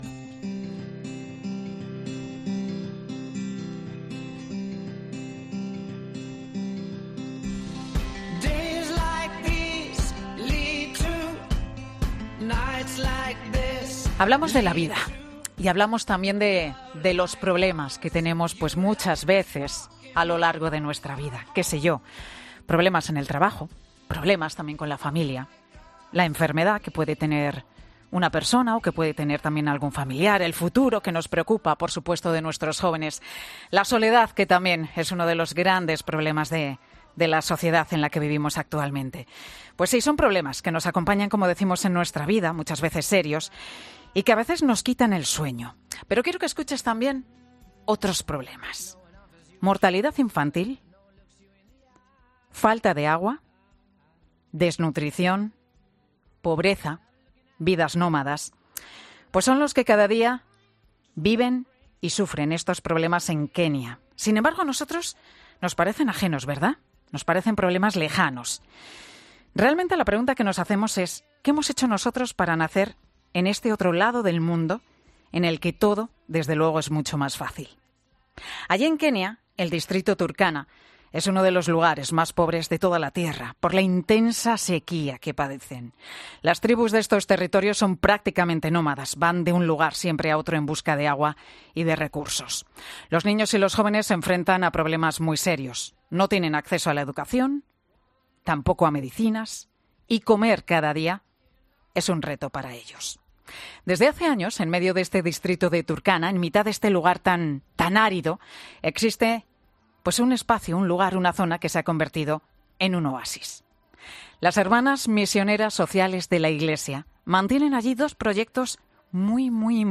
Hoy nos acompaña en Mediodía COPE. Asegura que el principal problema que encuentran allí es la alimentación: “El más difícil es la alimentación, la desnutrición infantil.